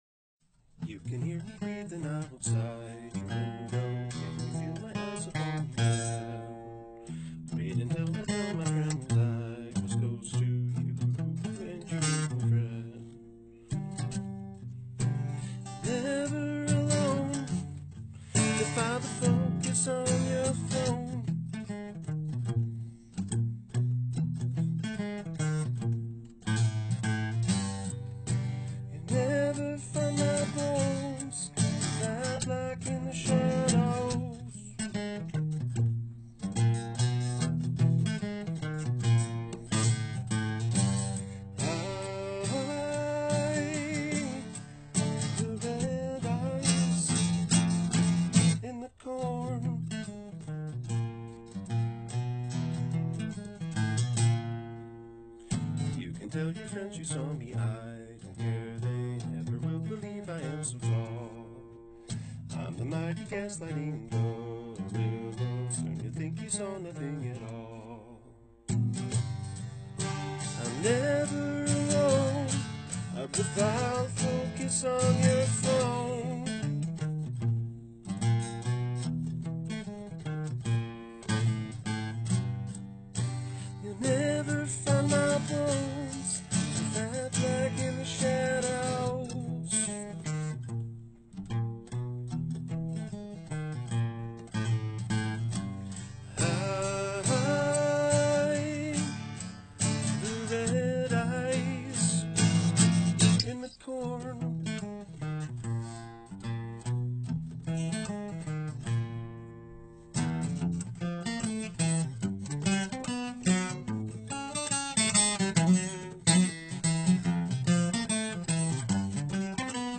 acoustic folk based jam